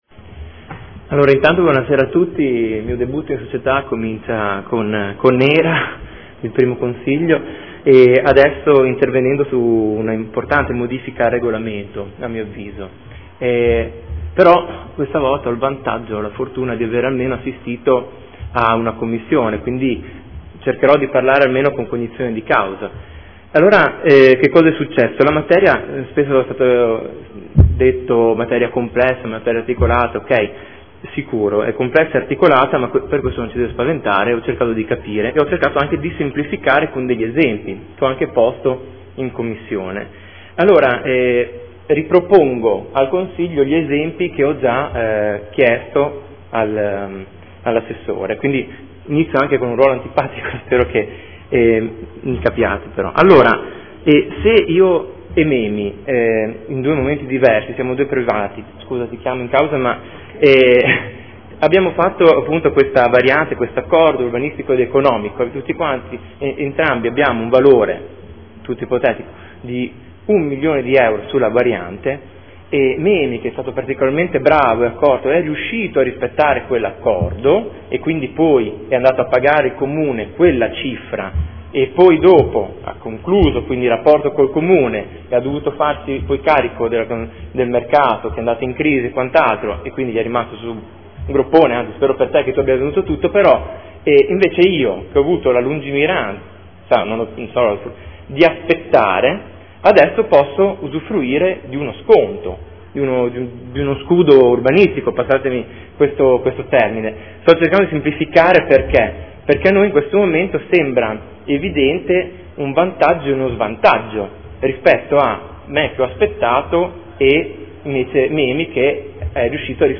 Seduta del 22 aprile. Proposta di deliberazione: Modifiche al Regolamento contenente i criteri e le modalità applicative dell’articolo 14.1 del RUE approvato con deliberazione di Consiglio comunale del 16.6.2008 e successive modificazioni.